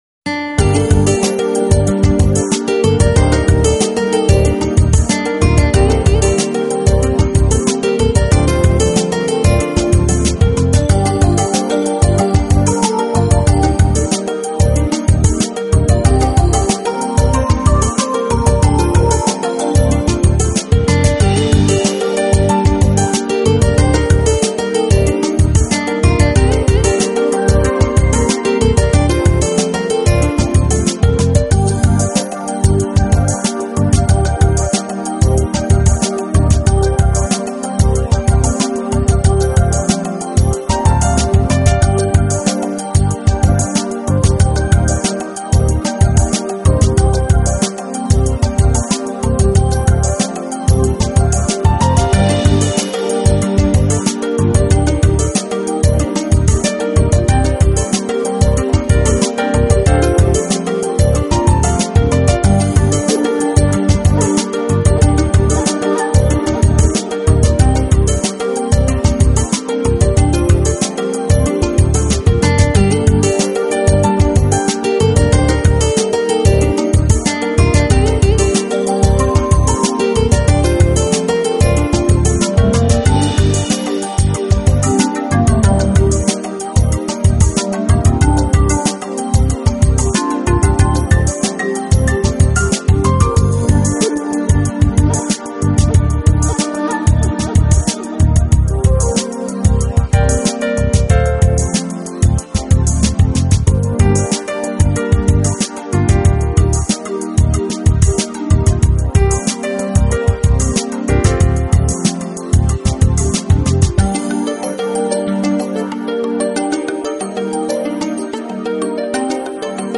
旋律轻柔流畅，器乐创新搭配，节奏舒缓时尚，魅力女声
如同和煦清爽的凉风与清凉透心的泉水流淌全身，令人舒适之极。